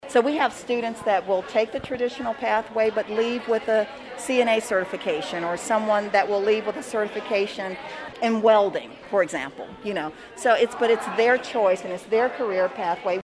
Geary County Schools USD 475 hosted a ribbon cutting and dedication ceremony for the 437,000 square foot facility.